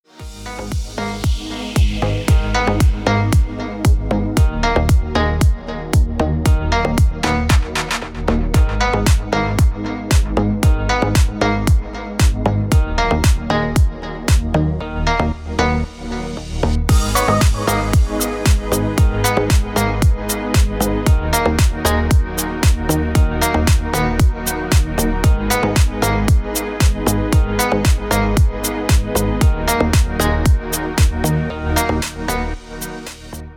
• Качество: 320, Stereo
remix
deep house
без слов
Indie Dance
Deep house отрезок на вызов